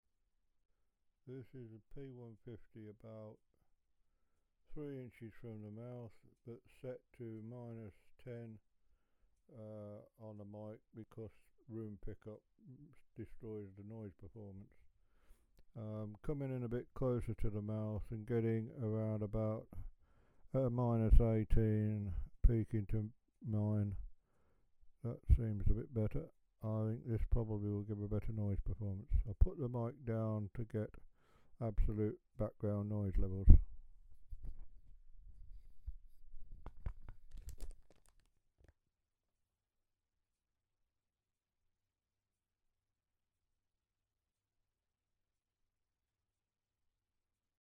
Mic is an AKG Perception 150 (SDC not NOTED for low noise) with the 10dB pad engaged because I was picking up room noise. The resultant noise floor is around -75dBFS and would be better with a quieter place (and a better mic!) because obviously the noise floor of Audacity (set to 32 bit float) Samplitude SE 8 and the KA6 is way better. I had no bother at all getting a good, clean level at 0 setting on the mic.